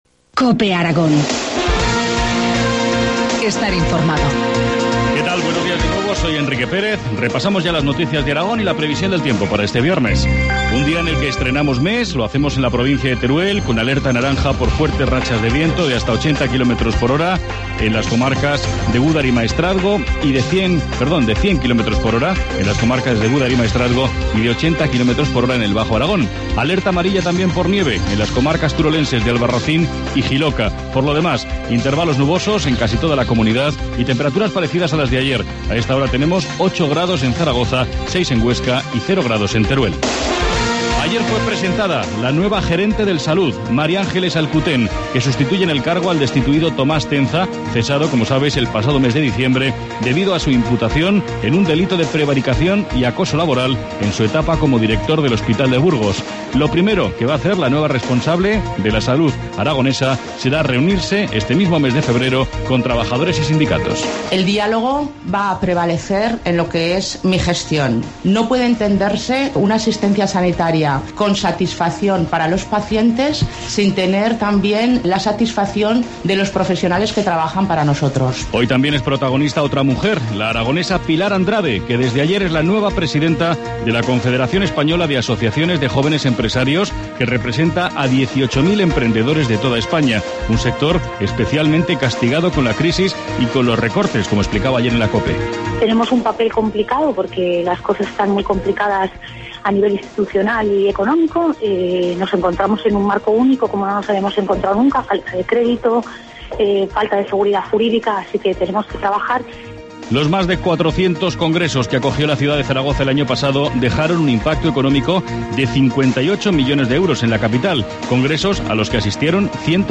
Informativo matinal, viernes 1 de febrero, 8.25 horas